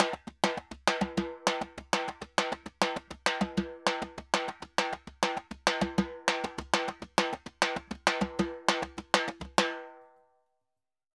Timba_Samba 100_1.wav